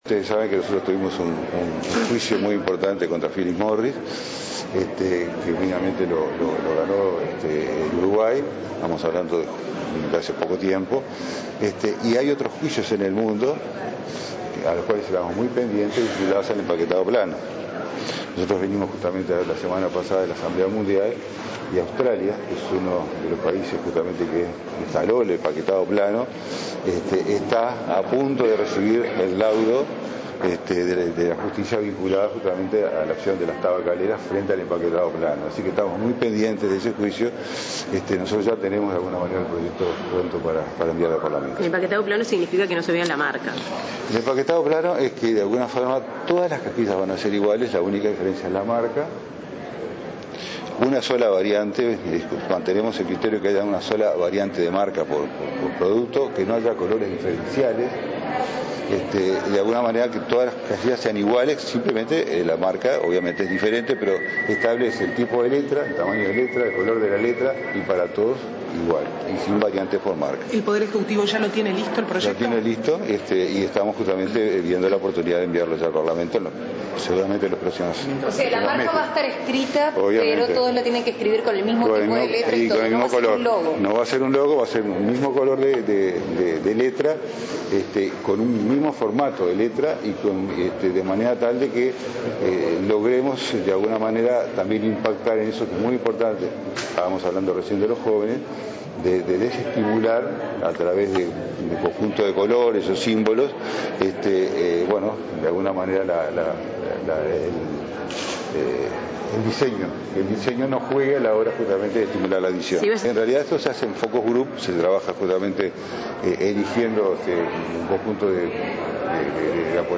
El ministro Jorge Basso adelantó que su cartera tiene pronto el proyecto de empaquetado plano de cigarrillos que será enviado al Parlamento en los próximos meses. El proyecto establece tamaño, tipo de letras y color sin variaciones por marcas. En otro orden, sostuvo a la prensa que se aplicarán las mismas normas para el cigarrillo electrónico ya que hay suficiente evidencia de que es igualmente perjudicial.